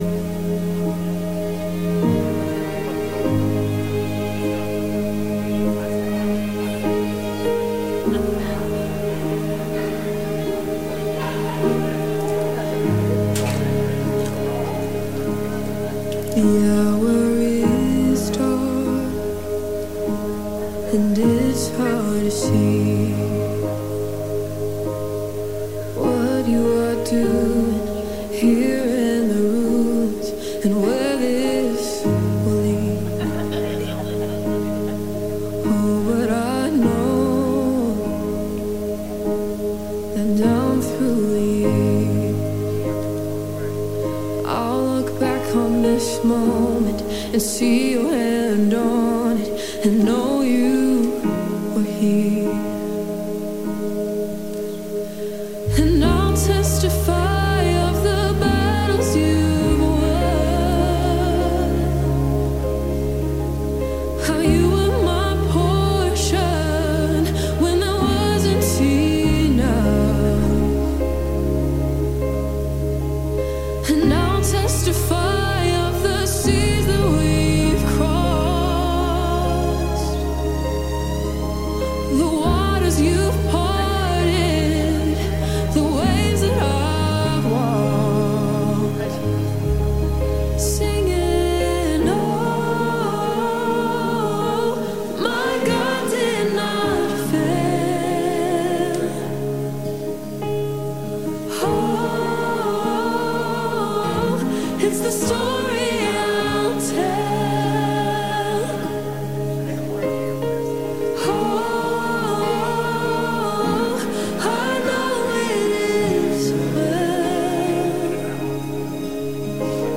Re - Center Sermon Series Part 2